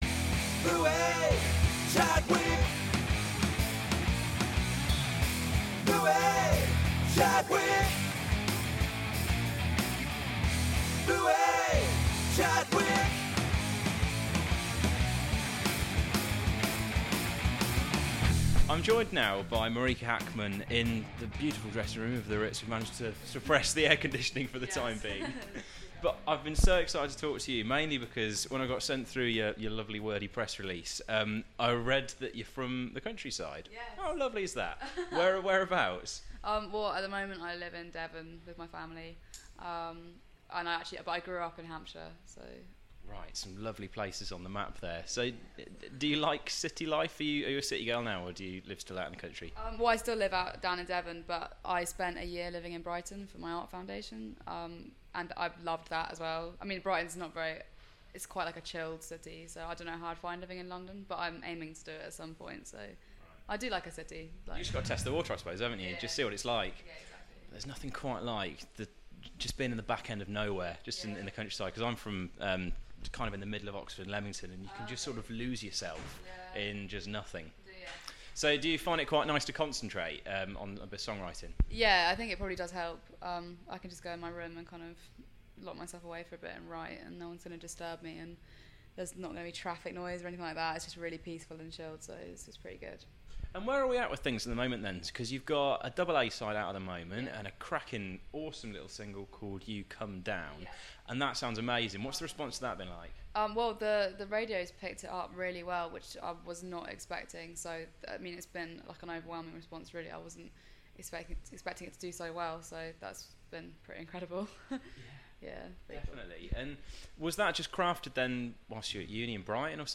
Oct12 - Marika Hackman in conversation
Marika Hackman is in conversation prior to going on stage in Manchester talking about her current projects!